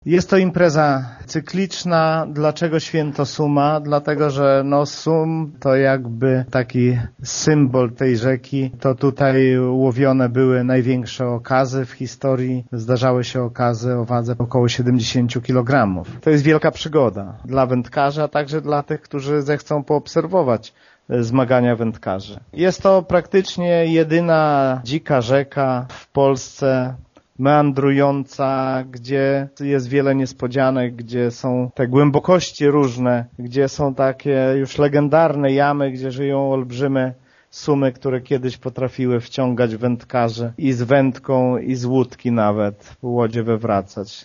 „Spotkanie w Prehoryłem to wyjątkowa propozycja. Tutejsze tereny to raj dla każdego kto lubi spędzać czas z wędką nad wodą” – przekonuje wójt gminy Lech Szopiński: